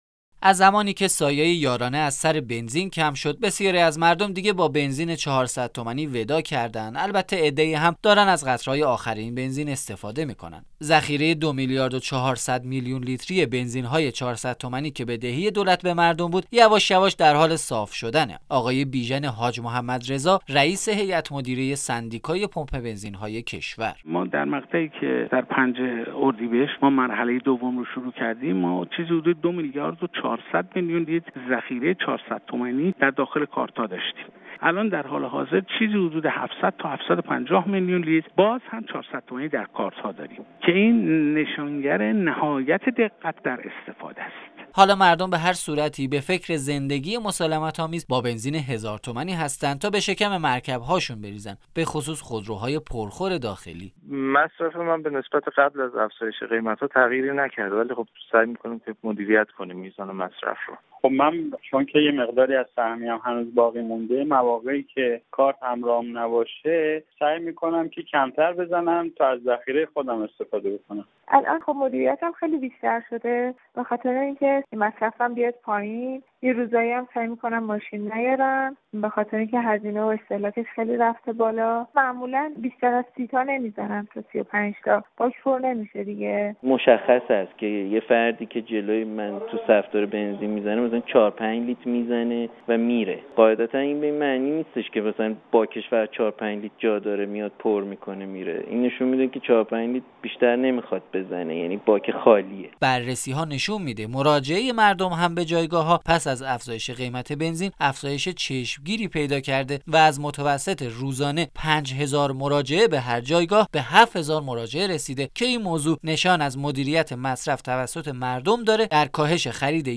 گزارشی درباره تغییر رفتار مردم پس از افزایش قیمت بنزین. منبع: رادیو ایران انتهای پیام/ خبرگزاری تسنیم : انتشار مطالب خبری و تحلیلی رسانه‌های داخلی و خارجی لزوما به معنای تایید محتوای آن نیست و صرفا جهت اطلاع کاربران از فضای رسانه‌ای بازنشر می‌شود.